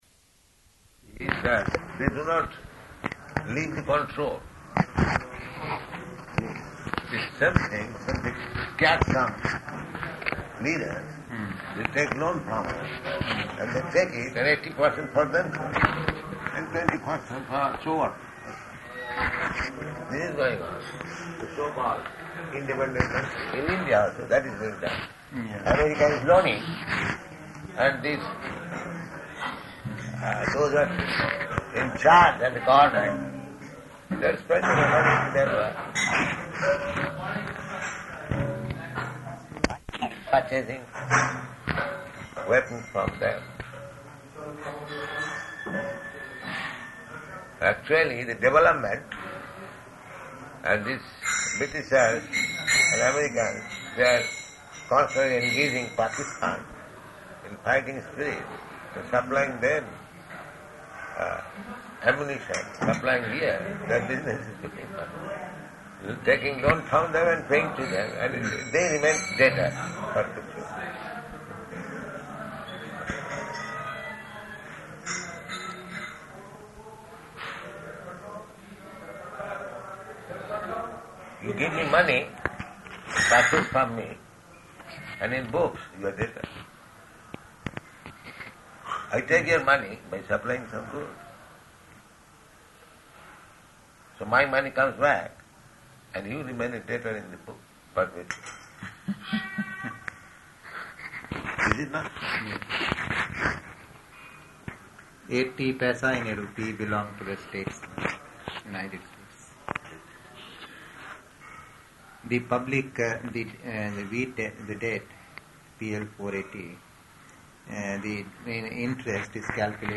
Conversation
Conversation --:-- --:-- Type: Conversation Dated: December 4th 1971 Location: Delhi Audio file: 711204R1-DELHI.mp3 Prabhupāda: These, they do not leave the control.